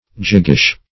jiggish - definition of jiggish - synonyms, pronunciation, spelling from Free Dictionary
Search Result for " jiggish" : The Collaborative International Dictionary of English v.0.48: Jiggish \Jig"gish\, a. 1.